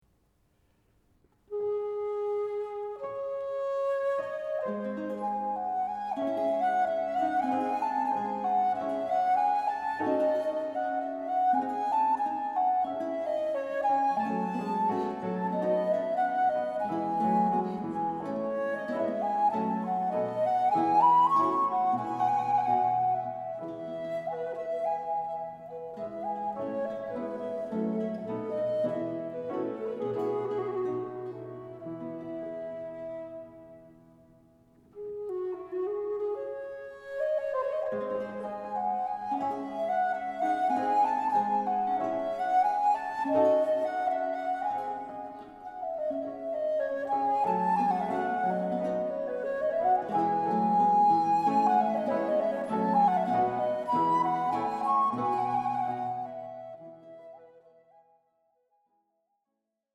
D minor, for traverse and basso continuo